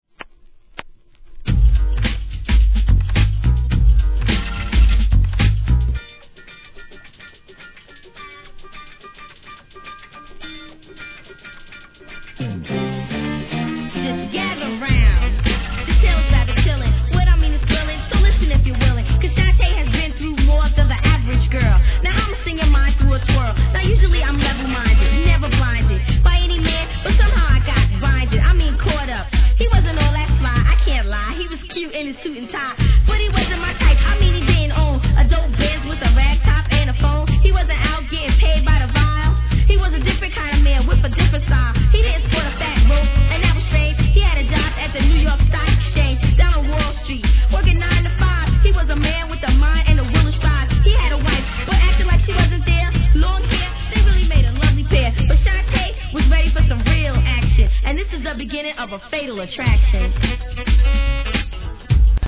HIP HOP/REGGARE/SOUL/FUNK/HOUSE/